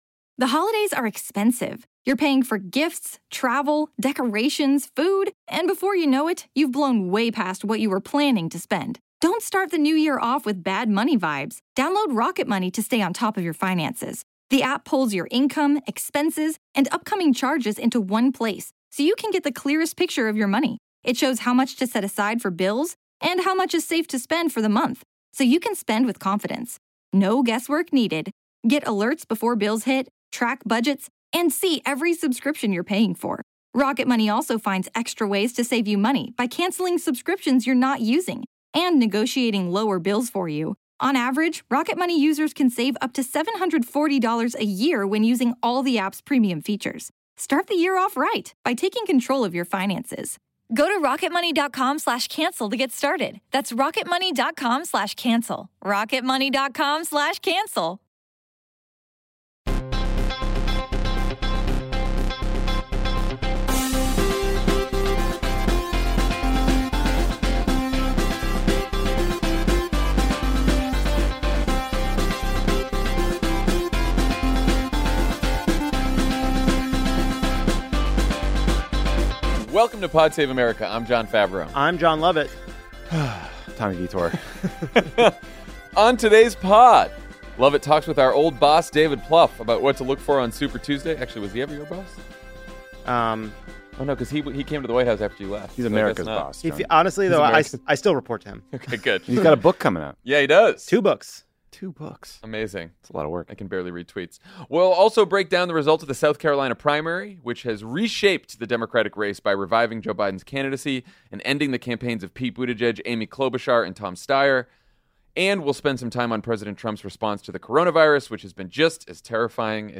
Joe Biden scores a decisive victory in the South Carolina primary, causing Pete Buttigieg, Amy Klobuchar, and Tom Steyer to end their campaigns. As the last four candidates look to Super Tuesday, the Trump Administration continues to bungle the response to the growing threat of coronavirus. Then David Plouffe talks to Jon L. about what to watch for on Super Tuesday and his new book, A Citizen’s Guide to Beating Donald Trump.